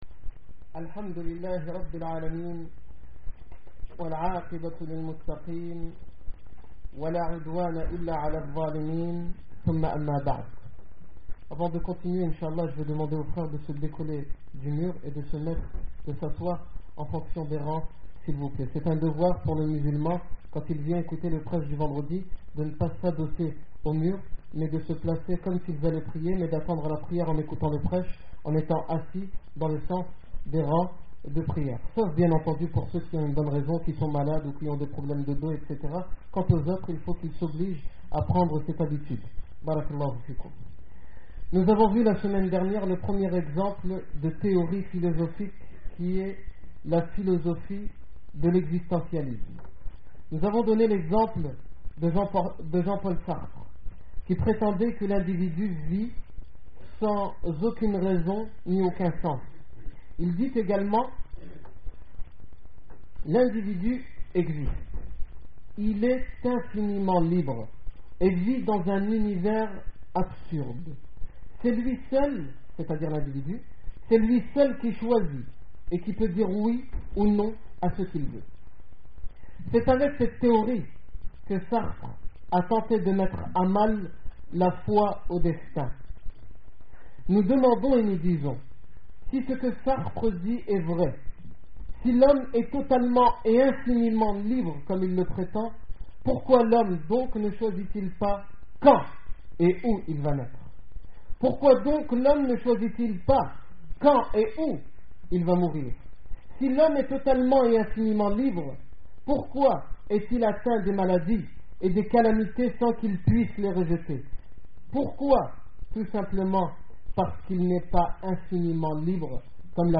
Discours du 25 avril 2008
Discours du vendredi